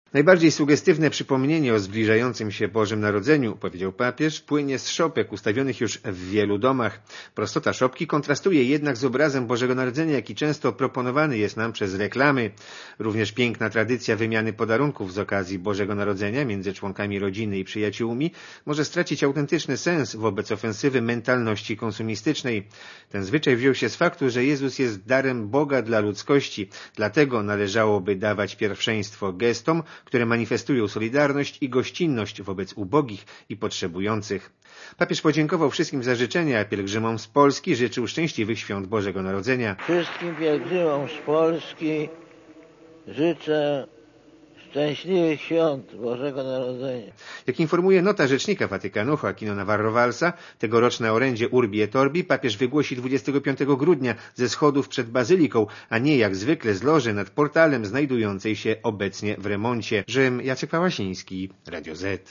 W przemówieniu wygłoszonym przed odmówioną wspólnie modlitwą Anioł Pański Jan Paweł II ostro skrytykował konsumpcyjne podejście do Świąt Bożego Narodzenia.